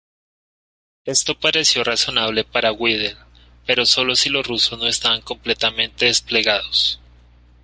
ra‧zo‧na‧ble
Pronúnciase como (IPA)
/raθoˈnable/